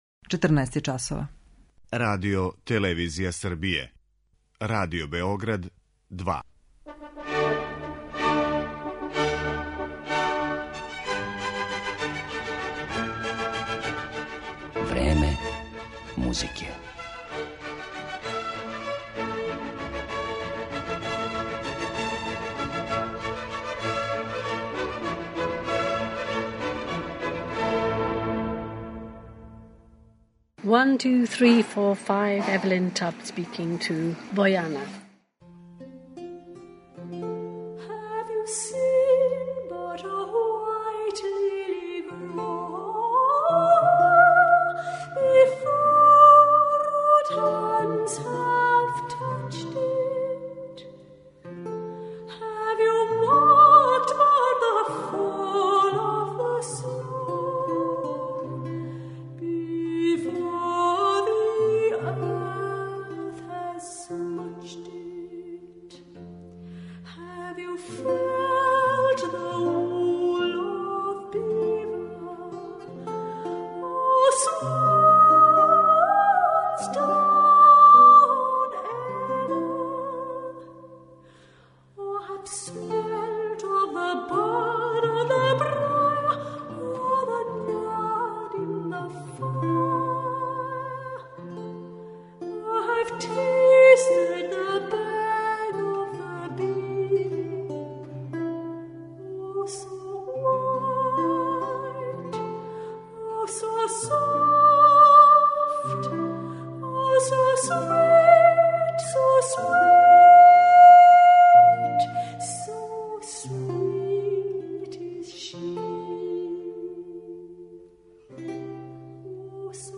Евелин Таб, британски сопран